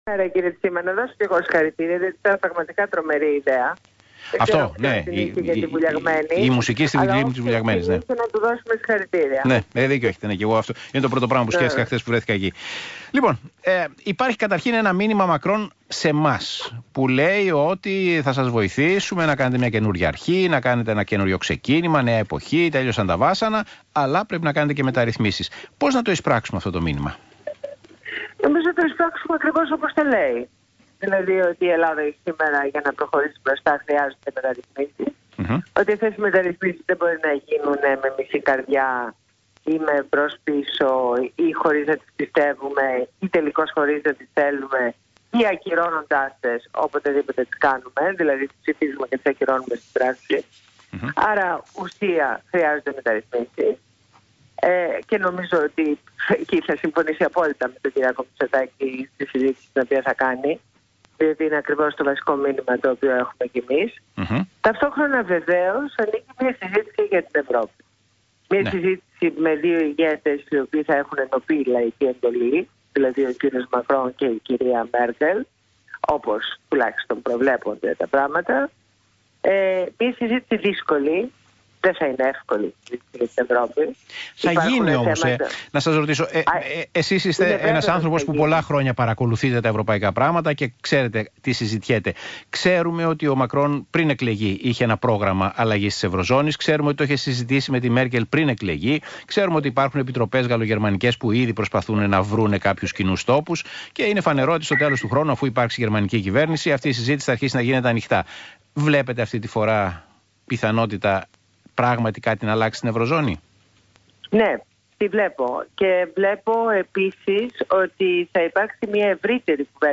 Ακούστε τη συνέντευξη (8/9) στο ραδιόφωνο του ΣΚΑΙ στην εκπομπή του Παύλου Τσίμα.